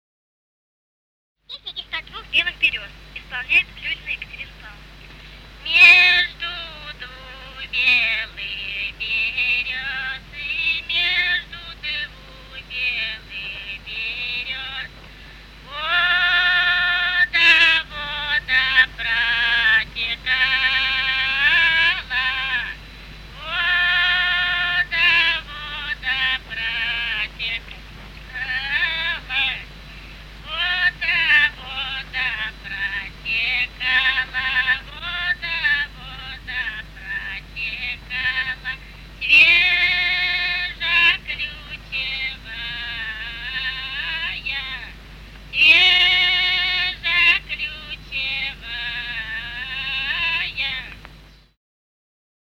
Русские народные песни Владимирской области 20а. Между двух белых берёз (хороводная) с. Коровники Суздальского района Владимирской области.